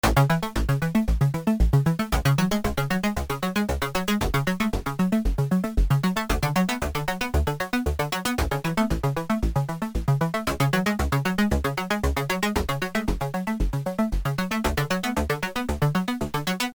Ich habe mal gerade das erste Preset des FM7 auf dem FM8 gespielt ich erkenne da überhaupt keinen Unterschied! FM7 und FM8 klingen exakt gleich.